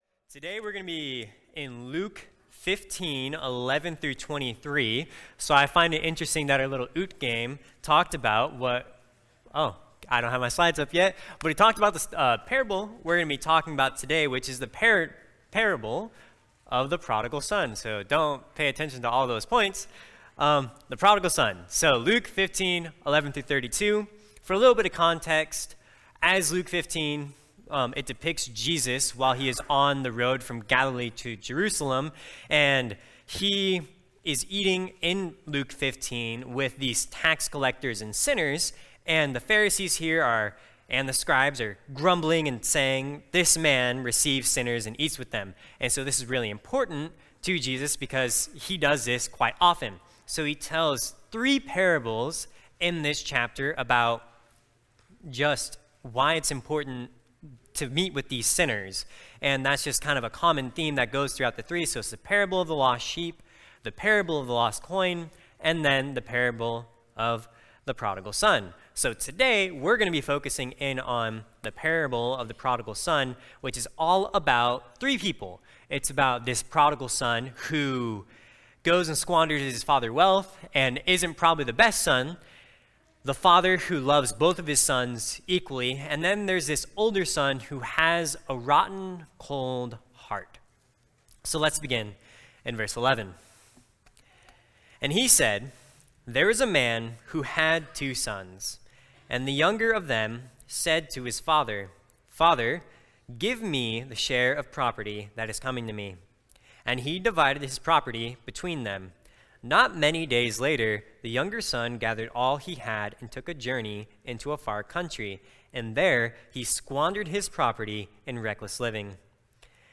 Youth Sunday – The Prodigal Son (Luke 15:11-32)
Guest Speaker